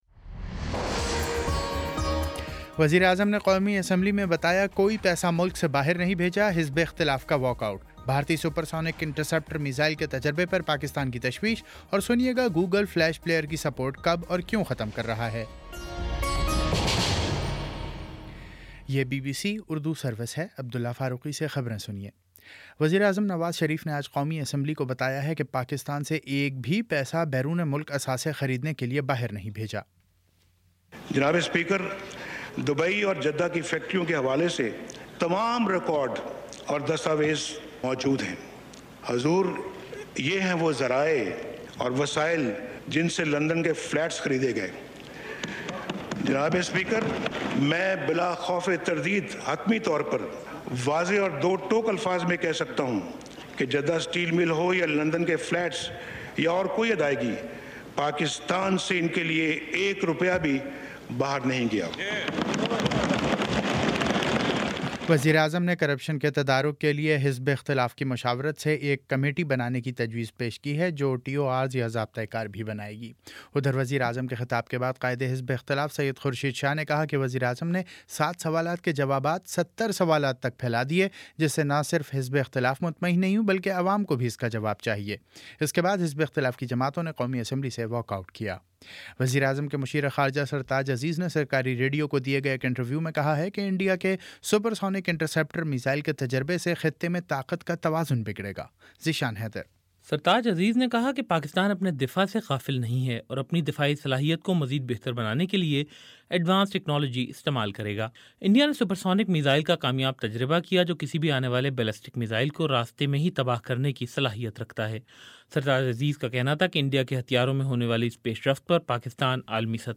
مئی 16: شام سات بجے کا نیوز بُلیٹن